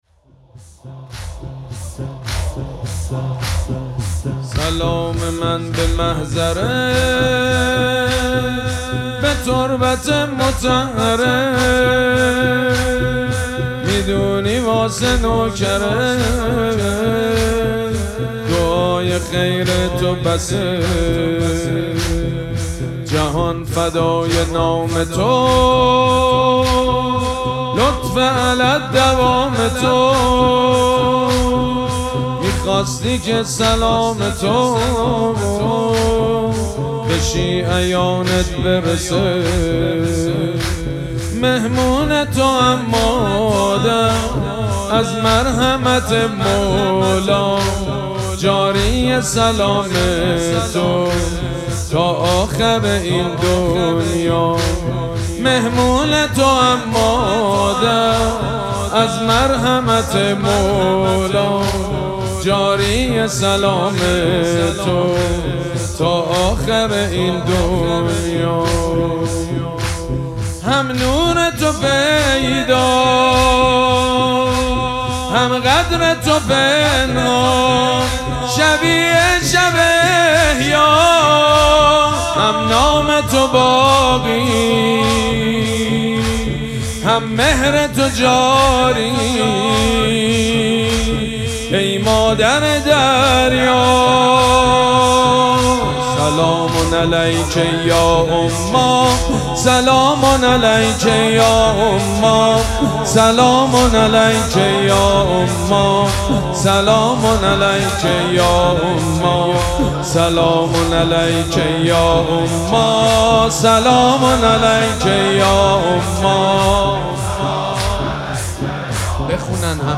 شب سوم مراسم عزاداری دهه دوم فاطمیه ۱۴۴۶
حاج سید مجید بنی فاطمه